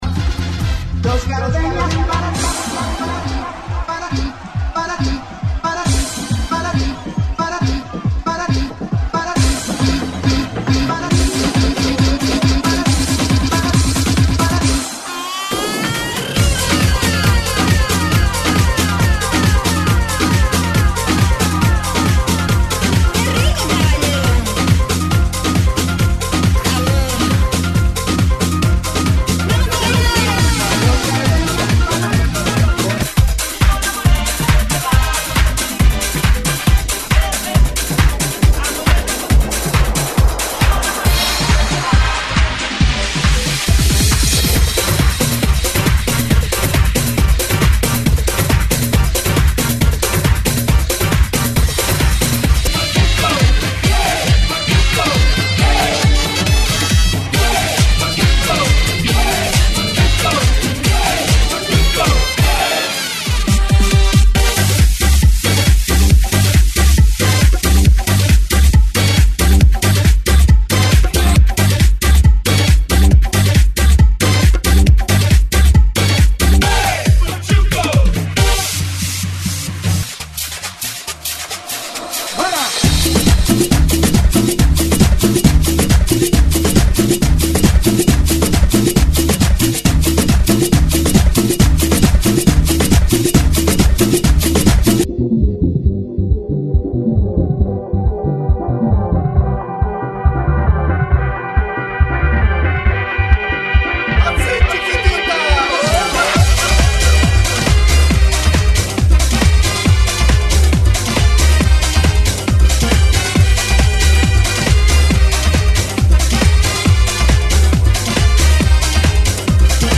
GENERO: ELECTRO – TROPICAL
ELECTRO-LATINO- TROPICAL,